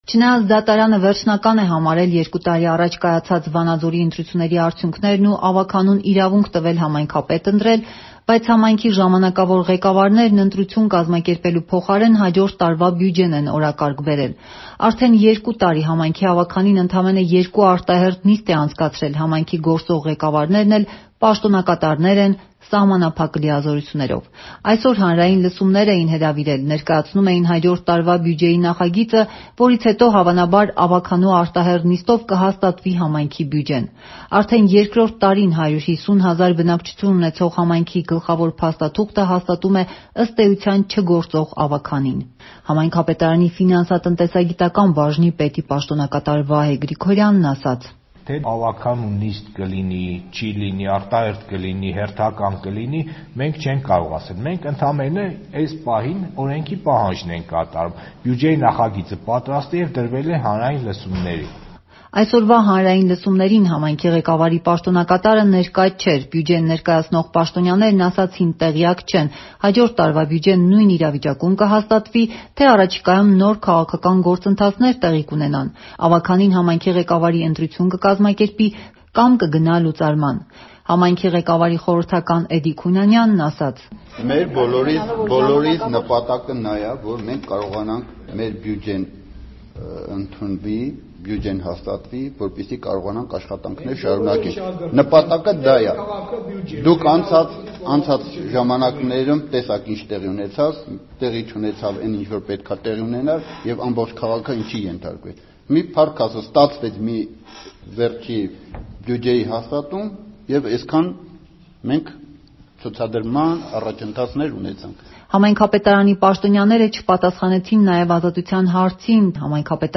«Ազատության» ռադիոռեպորտաժը՝ ստորև.